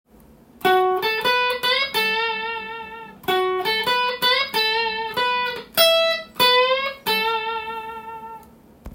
tab譜のkeyはAにしてみました。
Aメジャーペンタトニックスケール